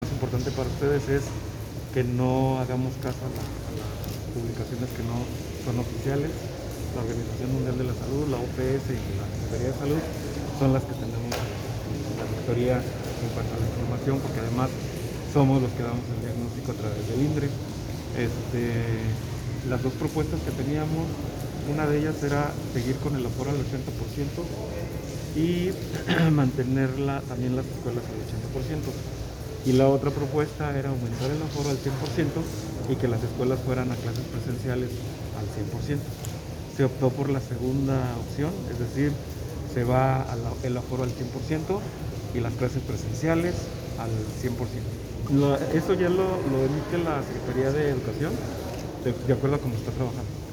Audio. Secretario de Salud Estatal, Felipe Fernando Sandoval Magallanes.